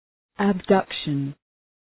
Προφορά
{æb’dʌkʃən}